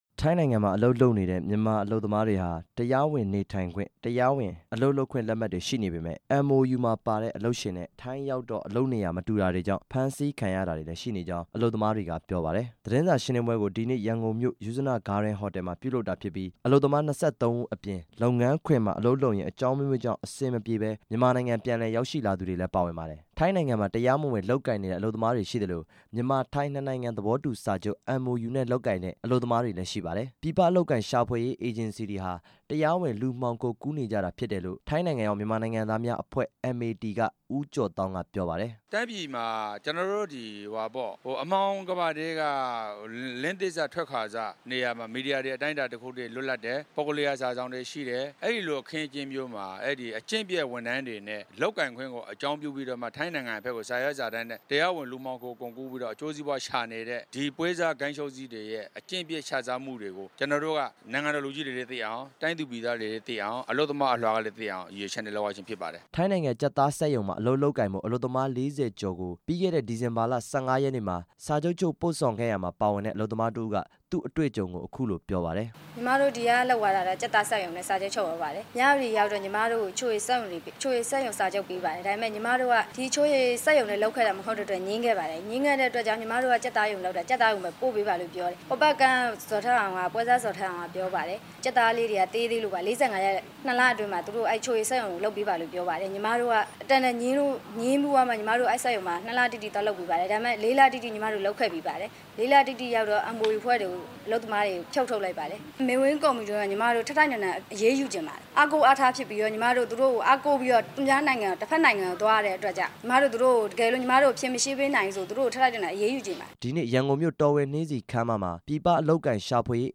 နှစ်နိုင်ငံသဘောတူစာချုပ် MOU ထိုင်းနိုင်ငံမှာ အလုပ်လုပ်နေကြတဲ့ မြန်မာနိုင်ငံသား အလုပ်သမားတွေက သူတို့ကြုံတွေ့ရတဲ့ အခက်အခဲတွေကို သတင်းစာရှင်းလင်းပွဲကျင်းပပြီး ရှင်းပြနေစဉ်မှာ ပြည်ပအလုပ်အကိုင်ရှာဖွေရေးအေဂျင်စီတွေ နဲ့ ထိုင်းနိုင်ငံက လုပ်ငန်းရှင်ဆိုသူတွေ ရောက်လာပြီး အချေအတင် စကားများခဲ့ကြပါတယ်။